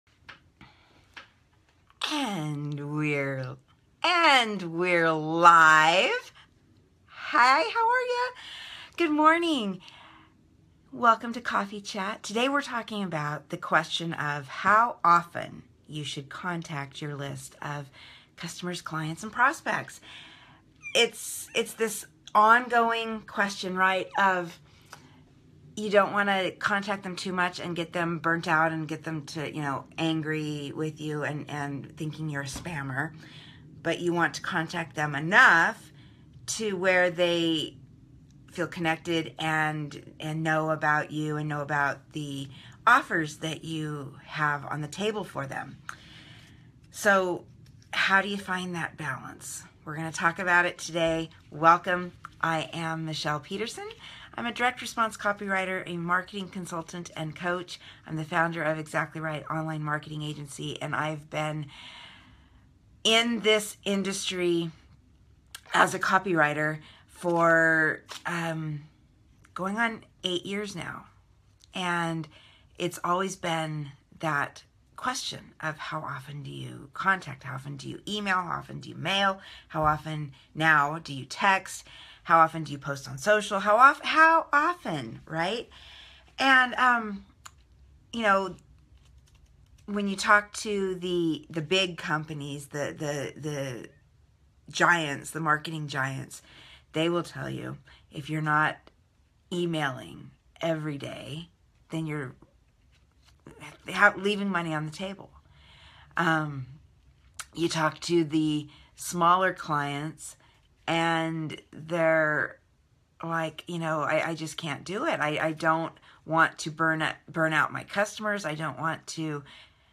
For your convenience, the audio and video recordings of this episode, which was originally live streamed on Dec. 16, 2016, can be found below.